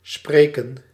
Ääntäminen
IPA: [pa.ʁɔl]